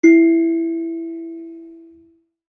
kalimba1_circleskin-E3-ff.wav